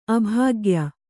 ♪ abhāgya